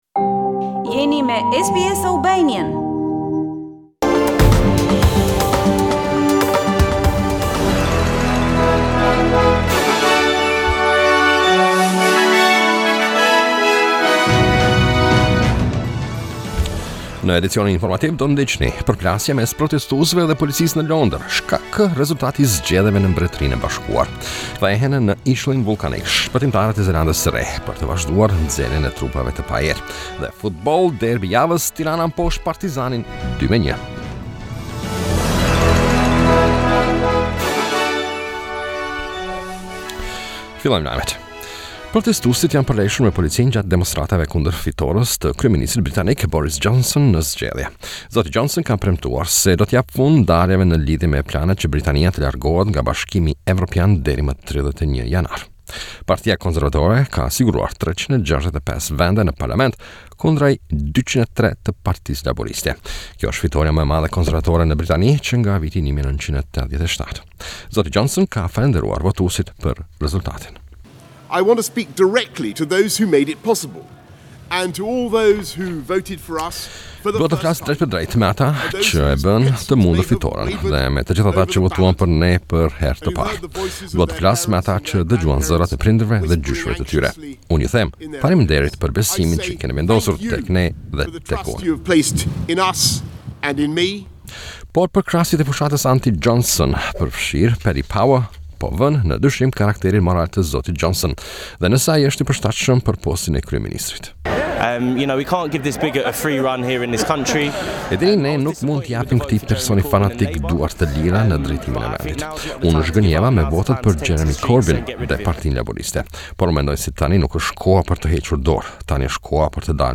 SBS News Bulletin - 14 December 2019